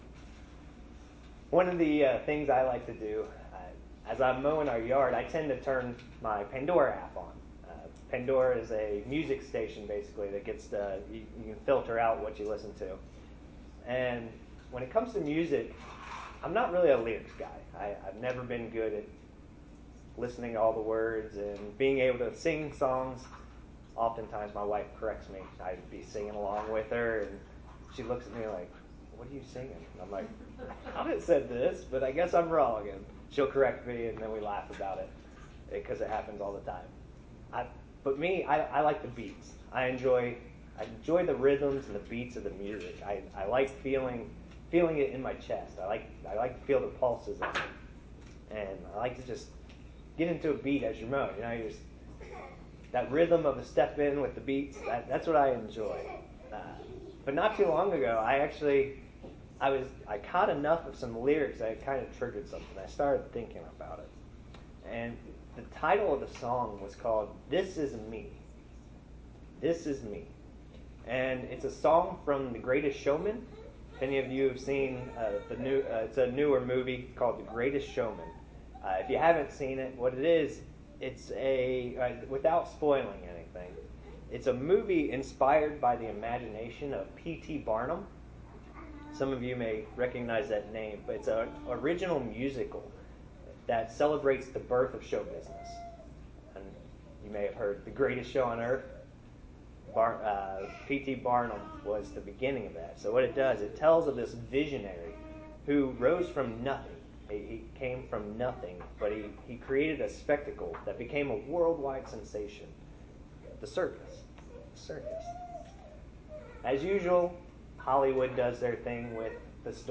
Please join us for this very interesting sermon about what God expects from us. Can we just be accepted for who and what we are? Does God expect us to change?